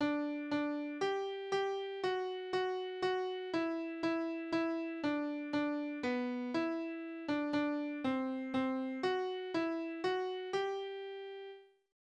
Tonart: G-Dur
Taktart: 3/4
Tonumfang: kleine Sexte
Besetzung: vokal